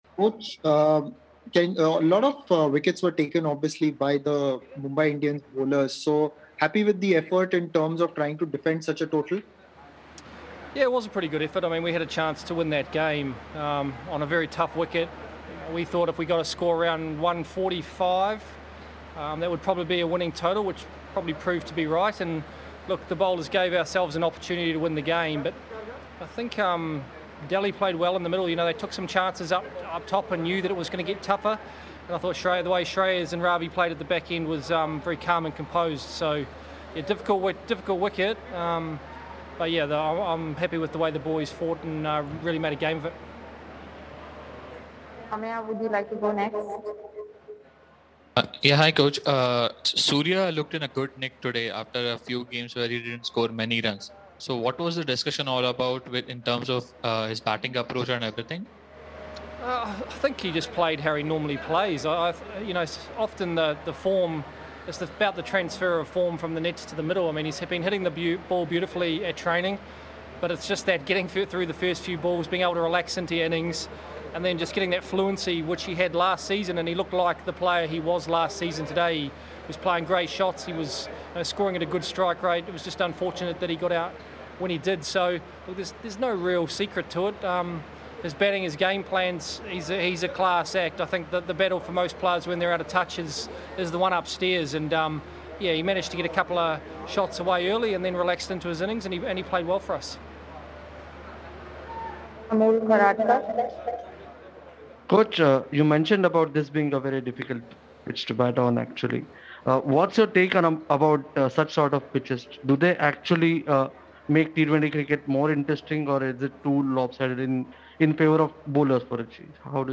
Shane Bond, Bowling Coach of Mumbai Indians speaks after losing the match to Delhi Capitals by 4 wickets
Shane Bond, Bowling Coach of Mumbai Indians addressed the media after the game.